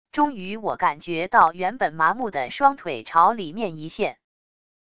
终于我感觉到原本麻木的双腿朝里面一陷wav音频生成系统WAV Audio Player